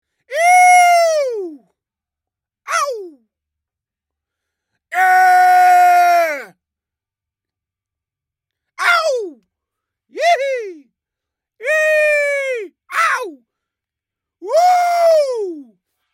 Download Mixkit Funny sound effect for free.